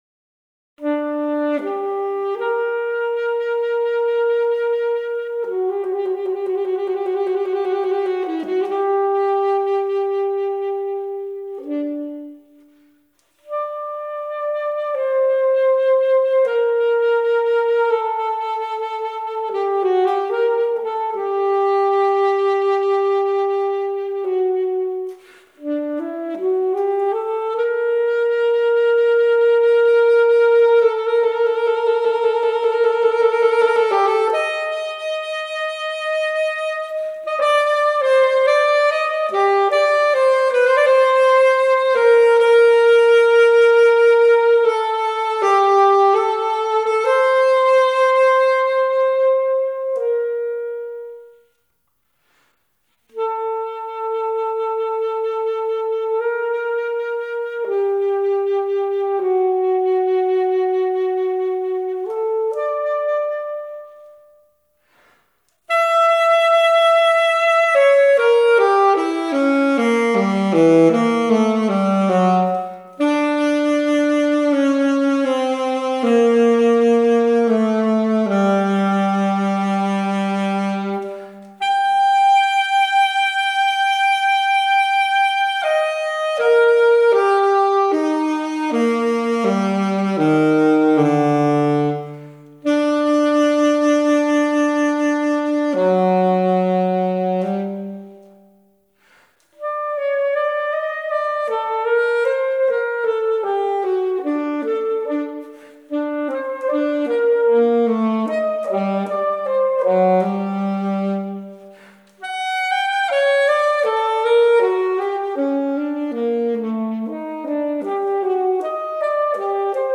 LARGO
(VIBRATO NATUREL ET SAXOPHONE)
une recherche d'expression, de timbre et de justesse, fondée sur le jeu du hautbois,